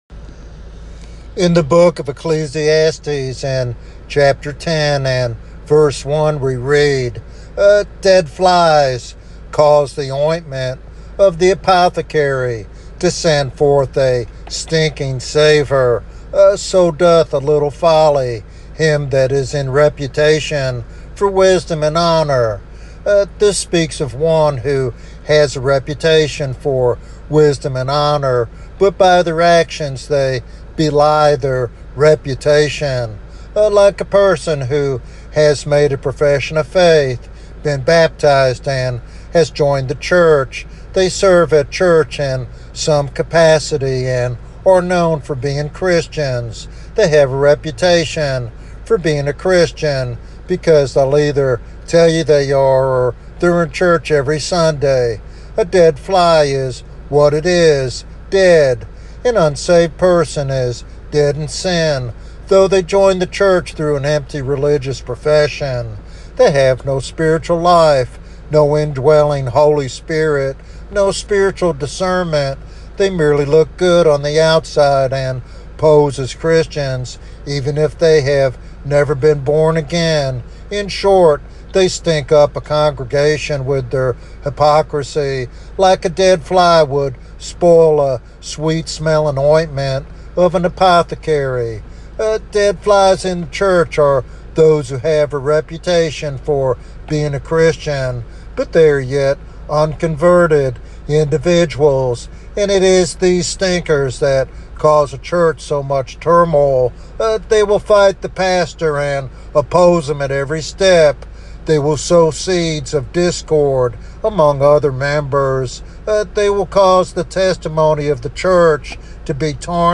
This sermon challenges listeners to examine their own faith and the impact they have on their church community.